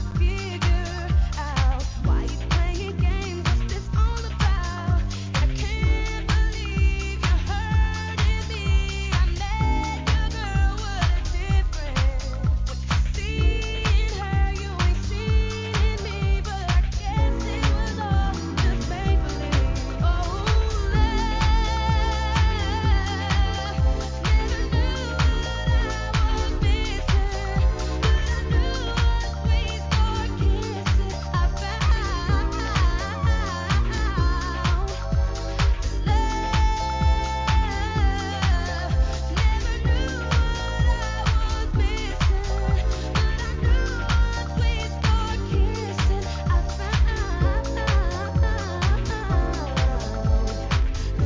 HIP HOP/R&B
2006年の大人気R&BのHOUSE REMIX!!